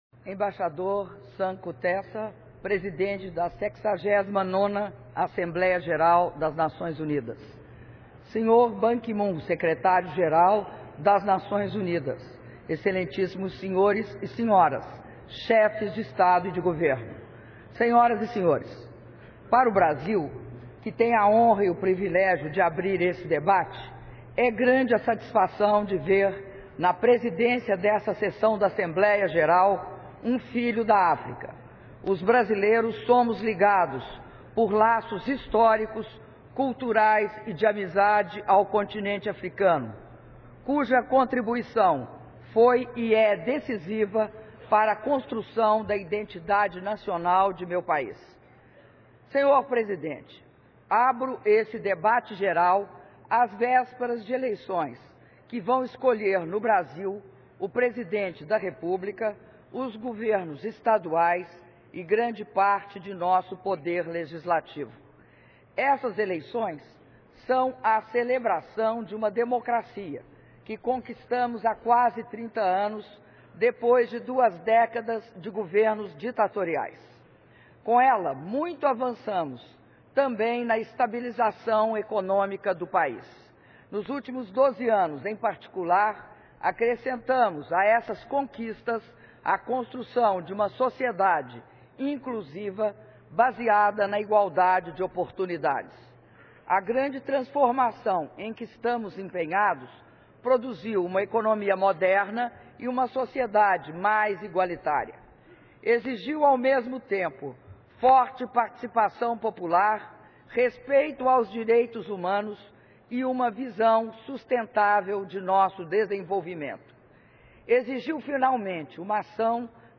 Áudio do discurso da Presidenta da República, Dilma Rousseff, na abertura do Debate de Alto Nível da 69ª Assembleia Geral das Nações Unidas (ONU) - Nova Iorque/EUA (23min39s)